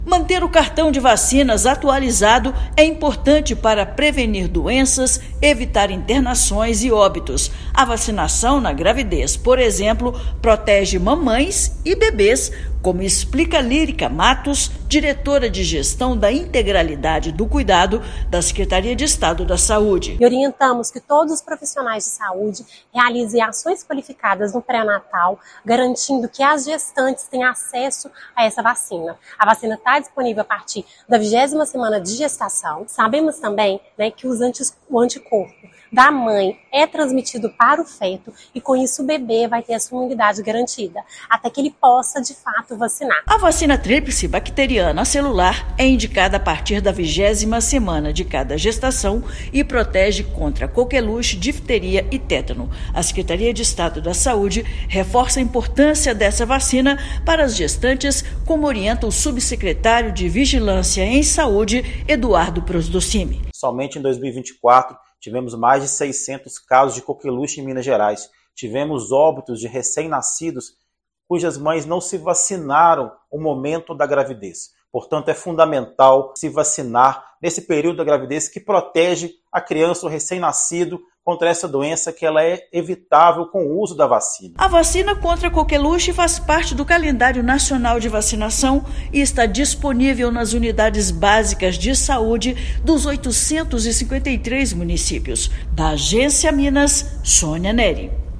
Vacina dTpa é recomendada a partir da 20ª semana de cada gestação para proteger mamãe e bebê da doença que é grave e pode levar a óbito. Ouça matéria de rádio.